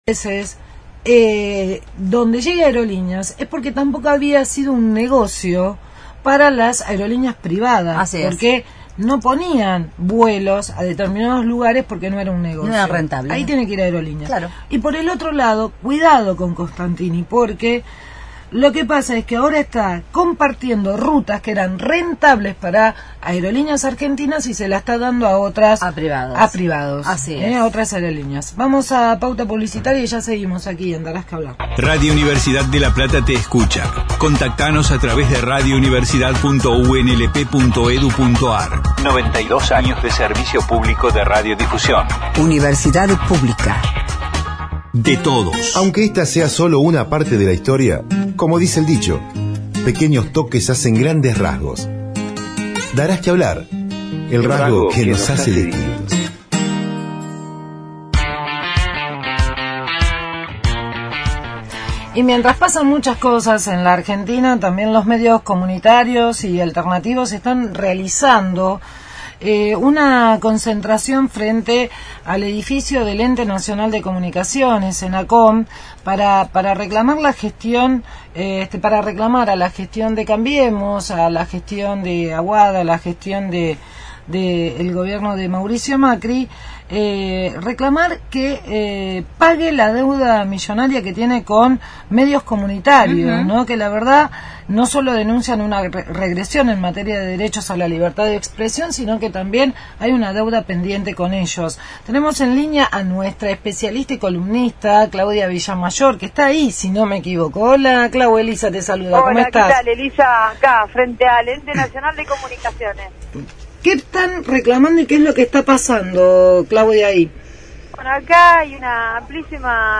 Darás que hablar/ AM 1390 https